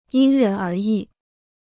因人而异 (yīn rén ér yì) Free Chinese Dictionary